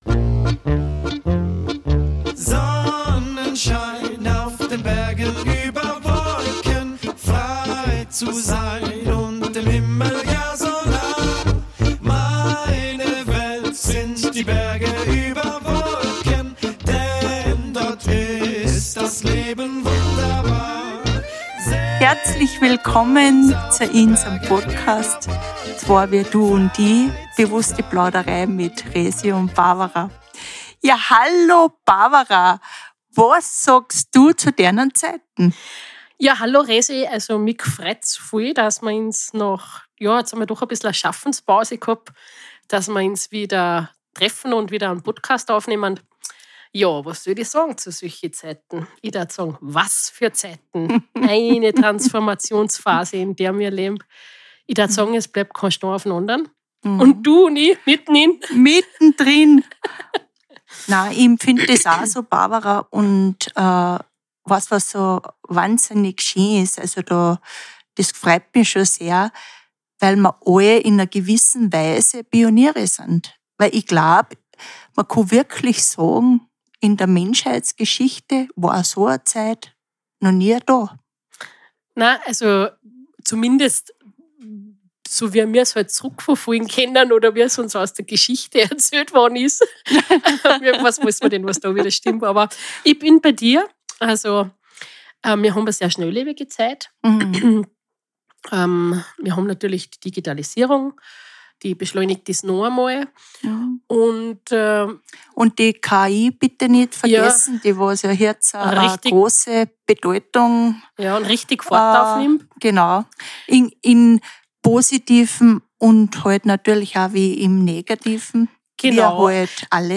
In gewohnt humorvoller Art